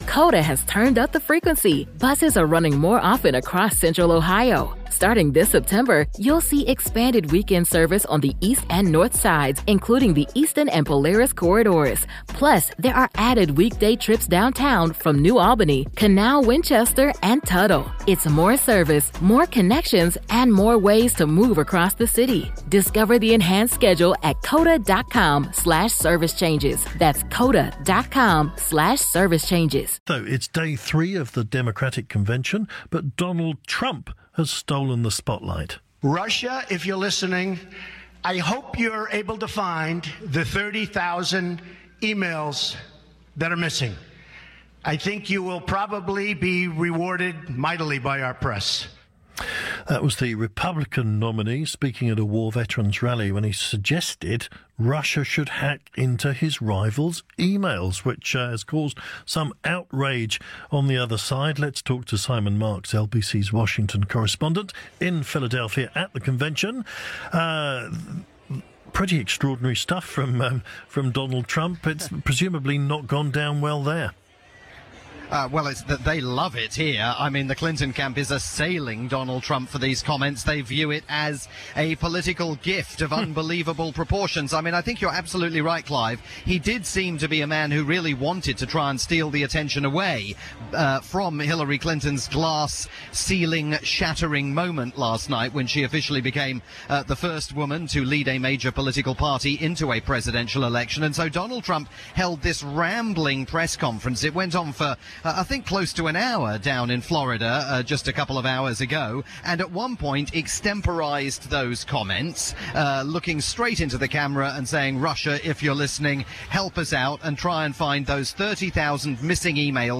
reported live for LBC